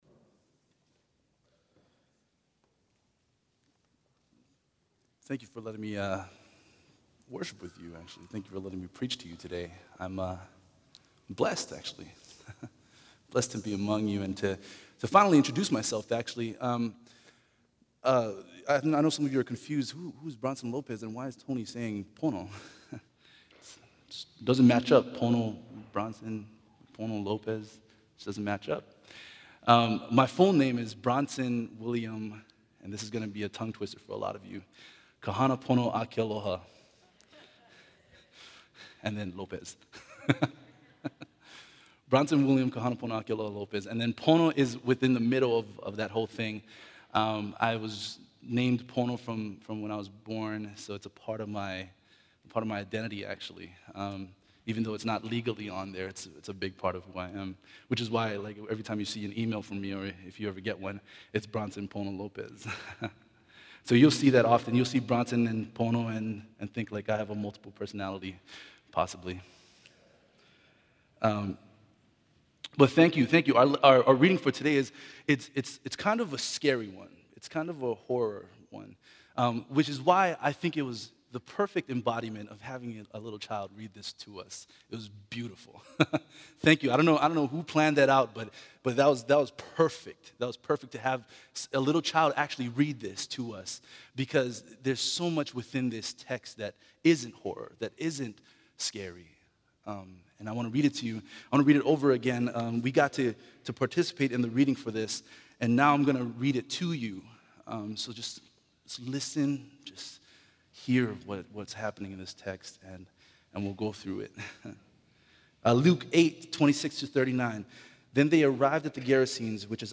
SERMONS 2013